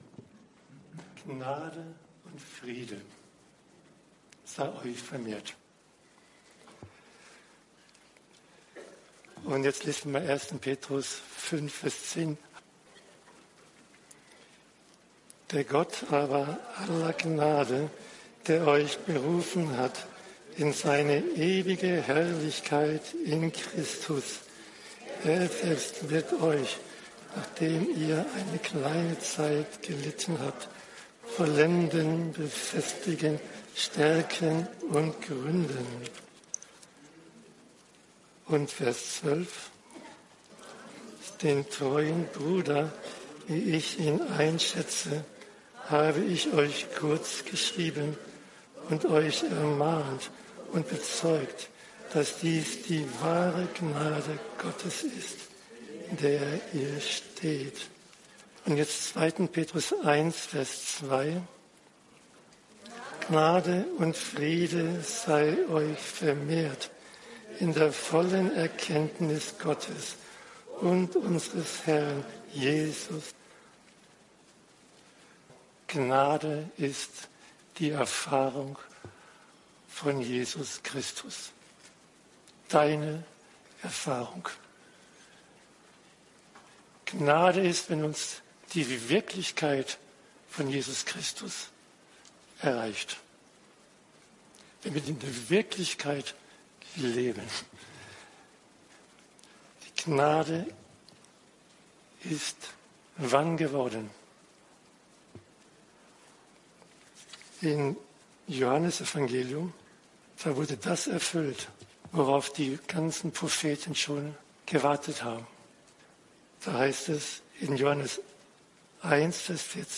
Gemeindeversammlung